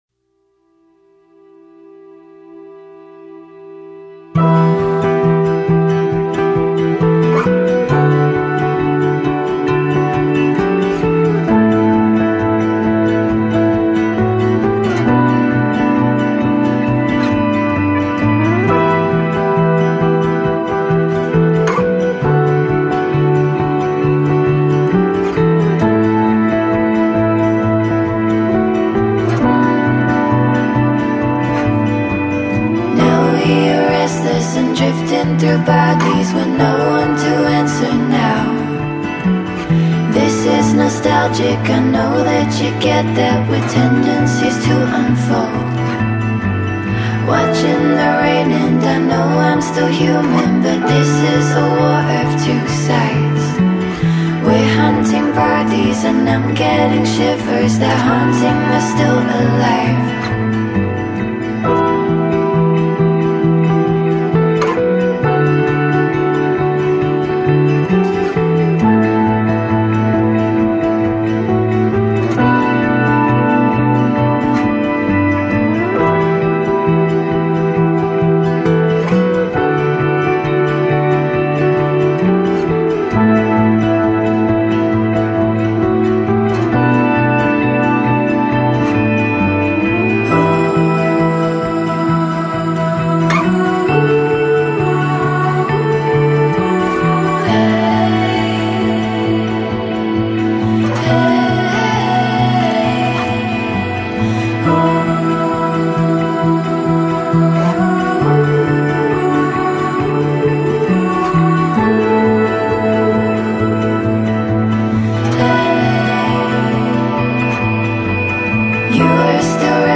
melodic electronica